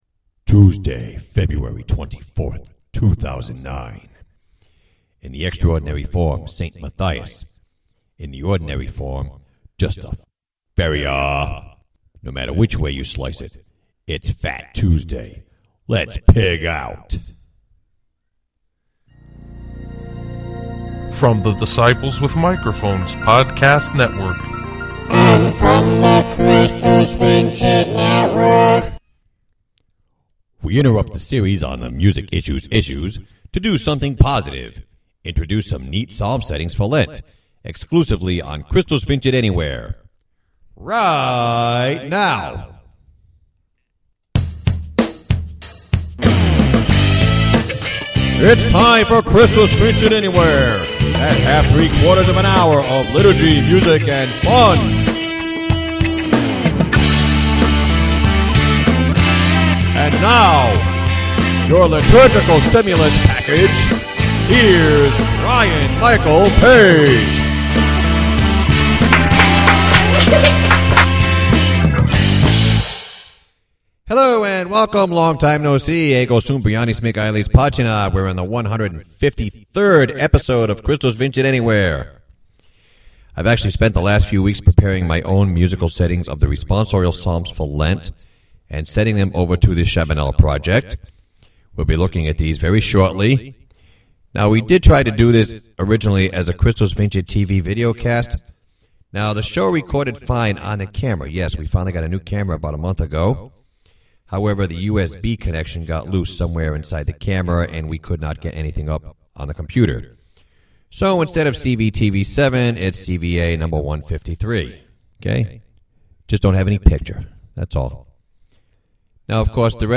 Don't Do This at Church (two clips) Christus Vincit Semi-Live at the Kitchen Table: the aforementioned original Psalm settings Music: 1-6.
Antiphon from the introit Esto Mihi (Chant, Mode IV)